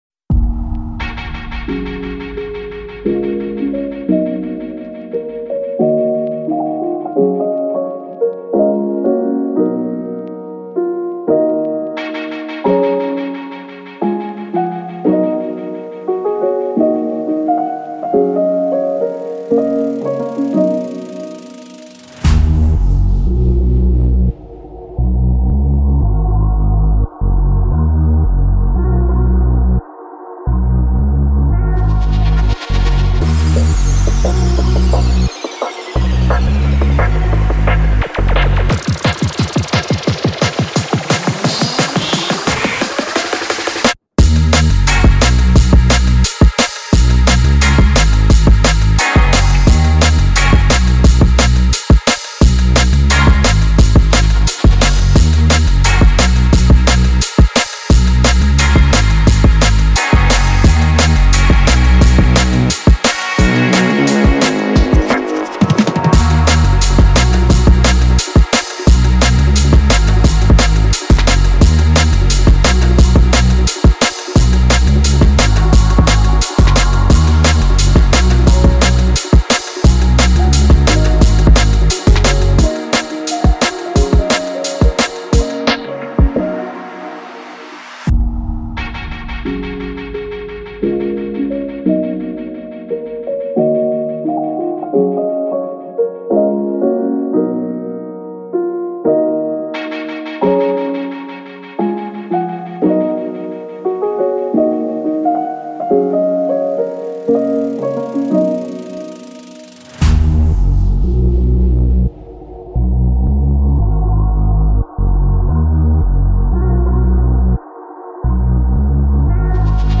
Pop Instrumentals